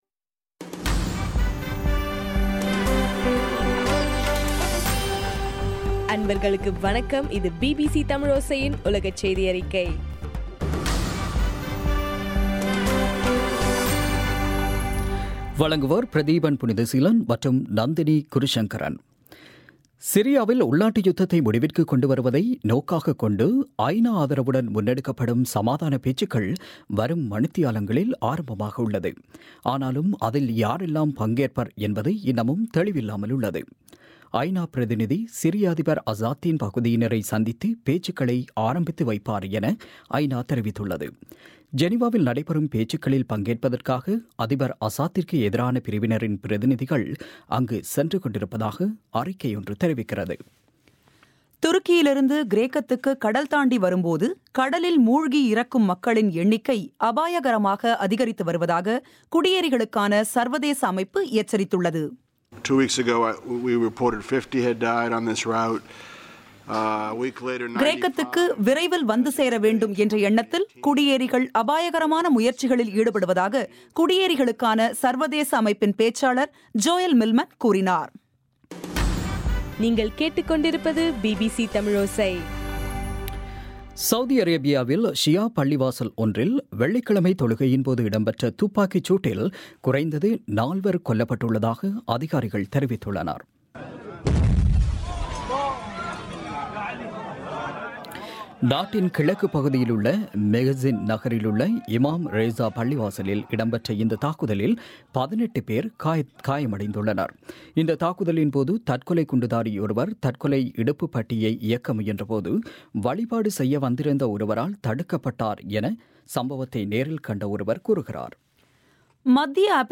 ஜனவரி 29, 2016 பிபிசி தமிழோசையின் உலகச் செய்திகள்